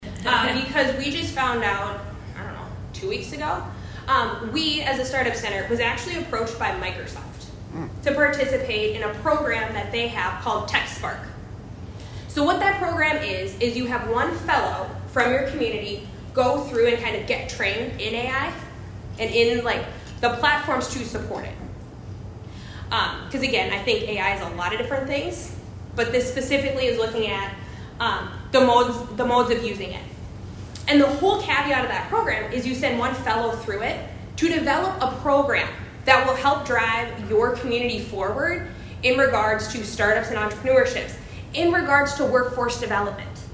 ABERDEEN, S.D.(HubCityRadio)- The Aberdeen Chamber of Commerce’s Chamber Connections Series continued Thursday at the K.O.Lee Public Library.